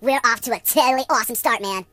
project_files/HedgewarsMobile/Audio/Sounds/voices/Surfer/Firstblood.ogg
Firstblood.ogg